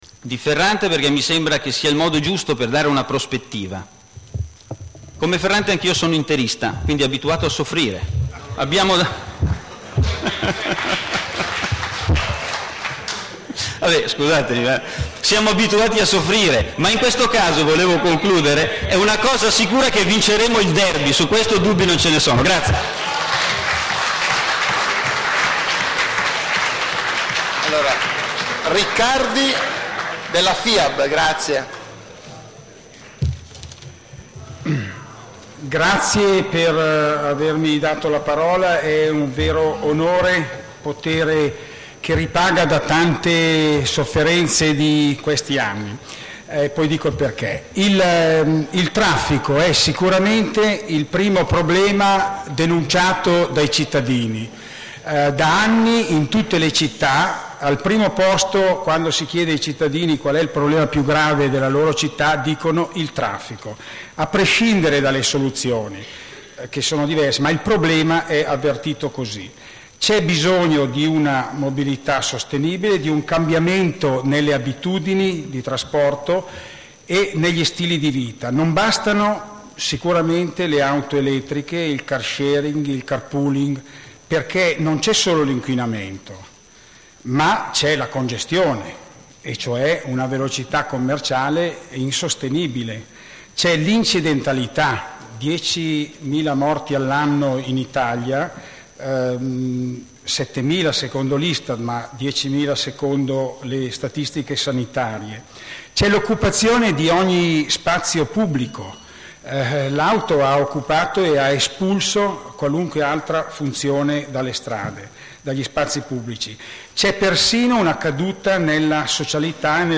14 Nov. 2005 - Intervento, a titolo personale, in occasione della candidatura di Ferrante a sindaco di Milano